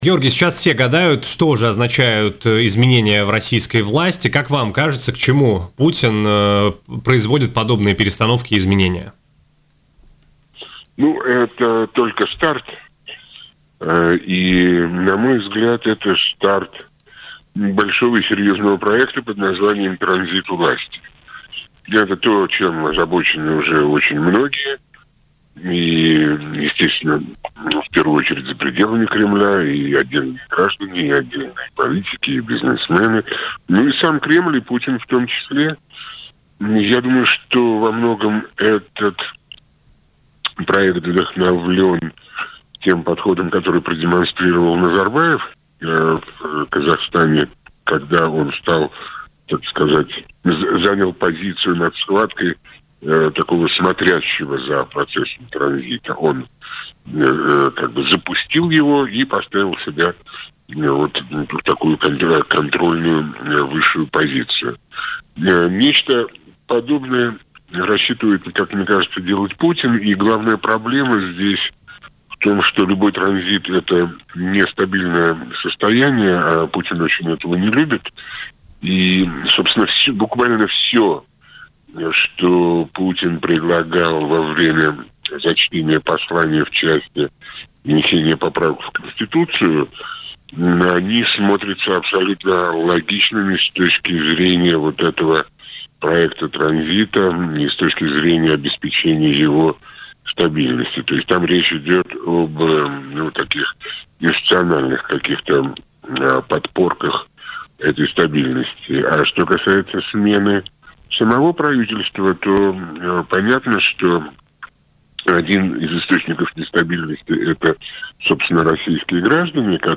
Такое мнение в эфире радио Baltkom высказал российский политолог и публицист Георгий Сатаров.